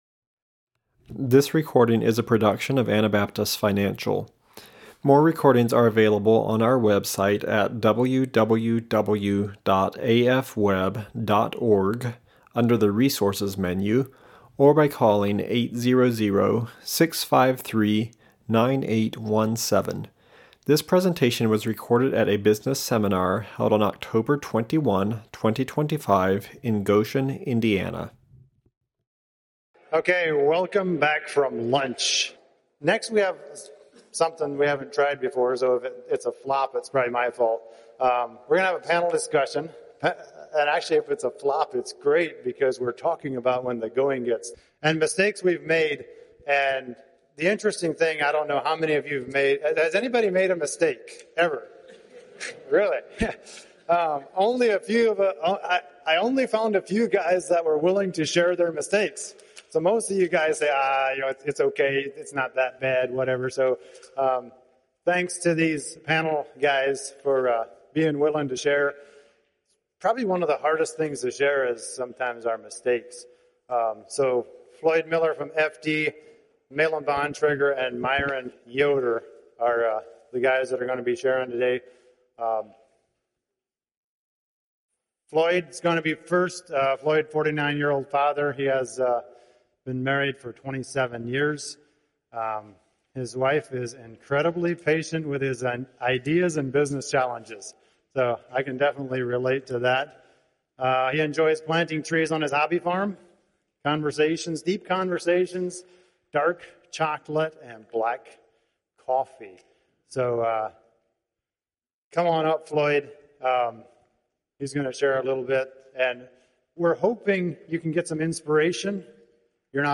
When the Going Gets Tough Panel Members 9318 / Indiana Business Seminar 2025 / Biblical Principles of Business and Work In this segment, seasoned Anabaptist businessmen share personal accounts of their seasons of struggle and how God used these experiences to mold them into better stewards.
when-the-going-gets-tough-panel-members.mp3